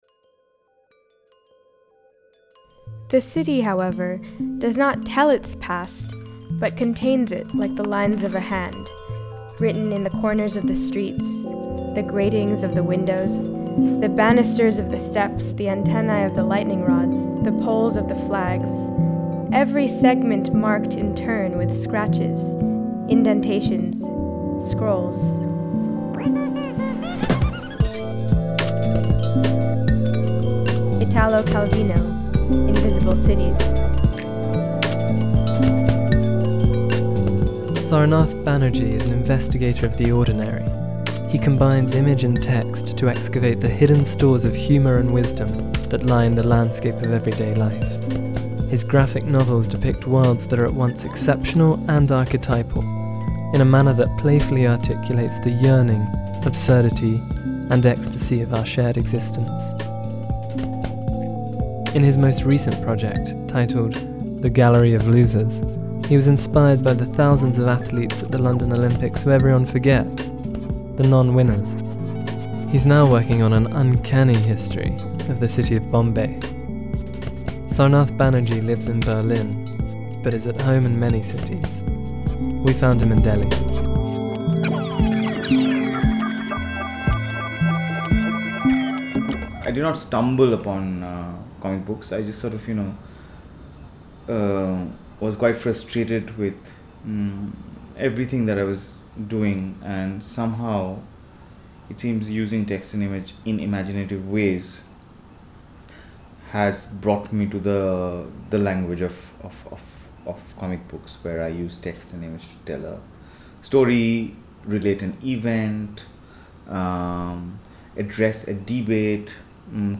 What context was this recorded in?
on a balmy evening in Delhi